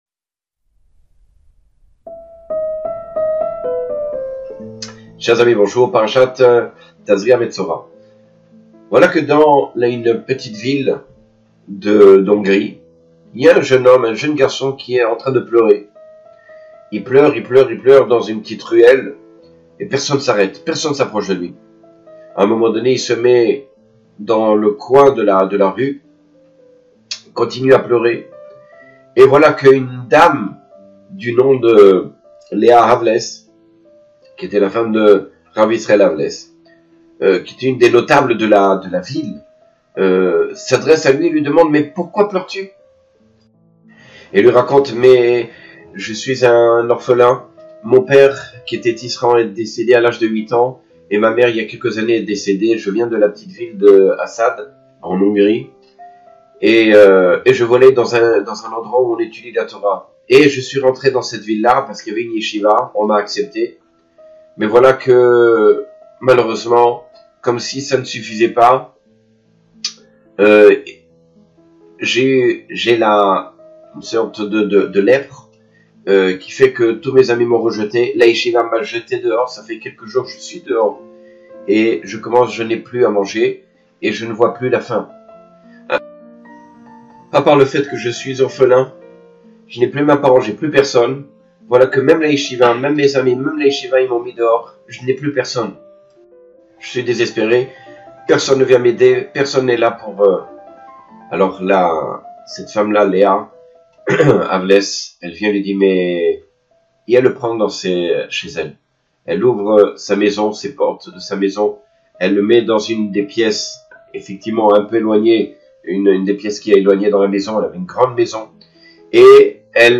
Chiour sur la parasha Tazria-Metsora.